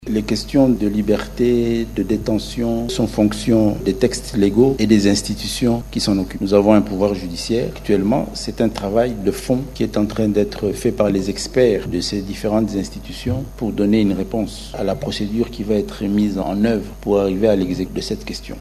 Lors du briefing de presse, organisé à Kinshasa, ce patron de la territoriale congolaise a indiqué que l’exécutif national a déjà mis à l’œuvre des experts pour l’exécution des mesures de confiance contenues dans l’accord de principes notamment sur la libération des prisonniers par l’une ou l’autre partie.
Suivez un extrait de la déclaration de Jacquemain Shabani.